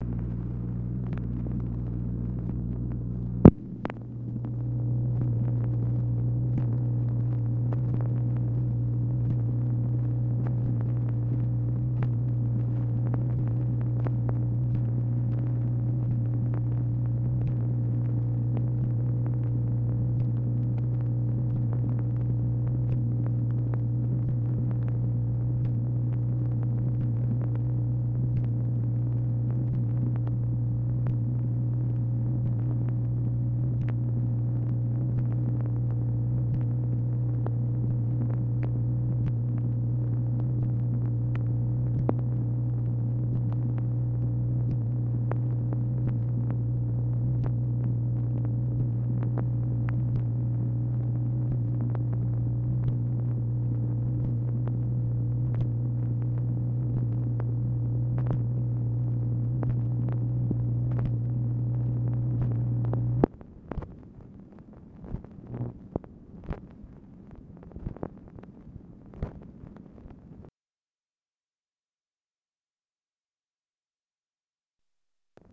MACHINE NOISE, February 8, 1964
Secret White House Tapes | Lyndon B. Johnson Presidency